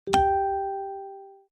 Facebook Notification - Sound Effect